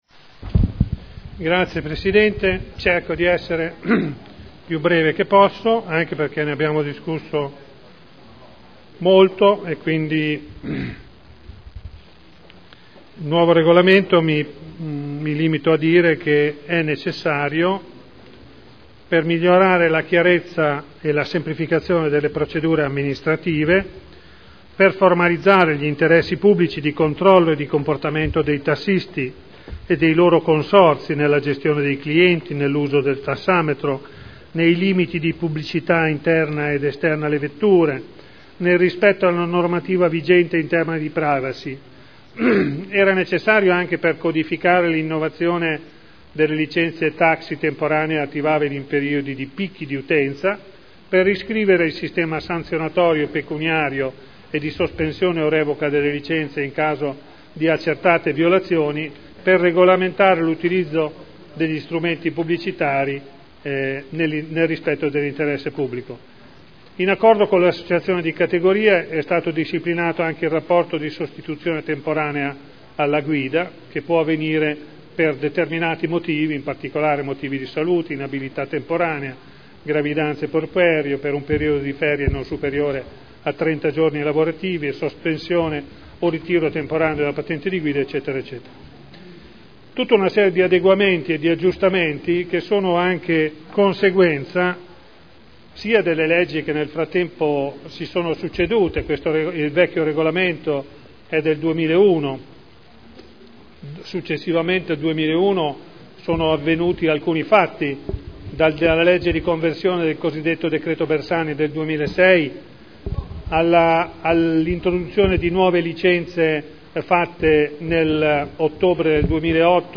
Seduta del 04/04/2011. Presentazione Delibera: Servizi di trasporto pubblico non di linea: taxi e noleggio con conducenti di veicoli fino a 9 posti – Approvazione nuovo Regolamento comunale e modifica art. 28 del Regolamento per l’applicazione della tassa per l’occupazione di spazi ed aree pubbliche e per il rilascio delle concessioni di suolo pubblico (Commissione consiliare del 15 e del 29 marzo 2011)